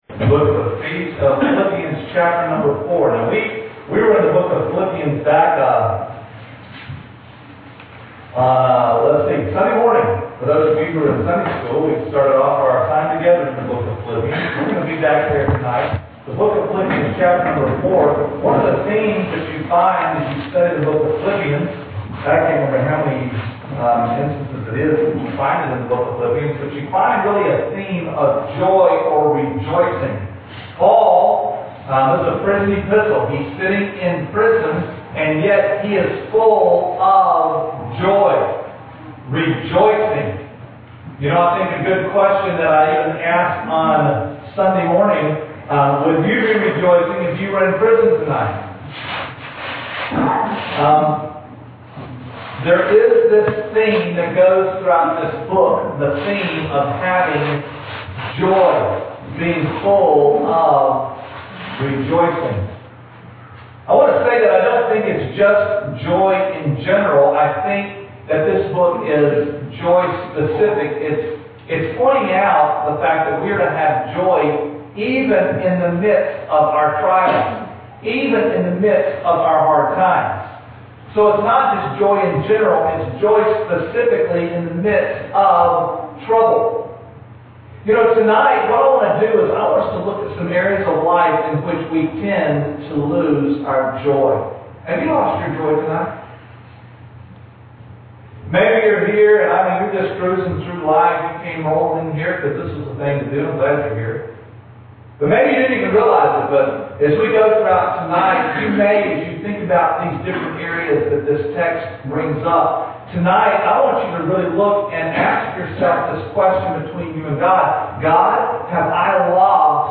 Passage: Philippians 4:4-13 Service Type: Revival Service